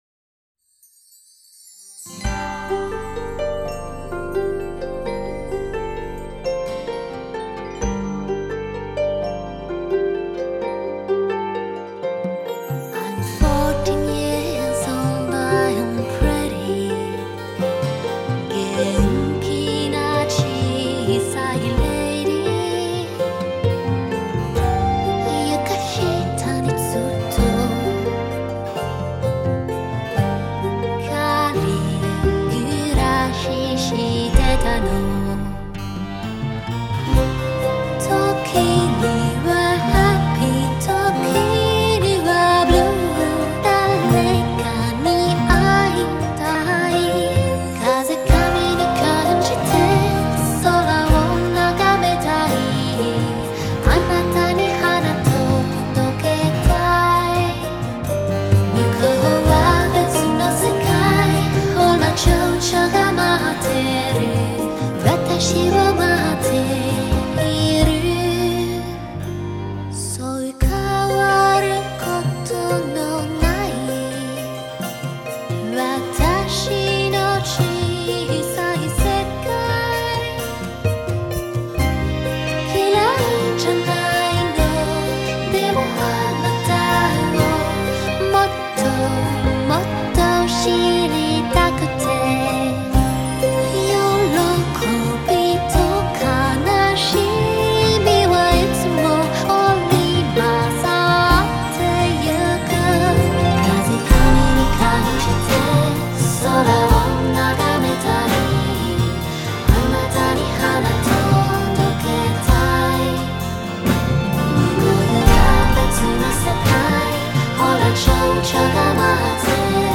아, 그리고 무엇보다 중간 중간 나오는 음악이 참 어울린다.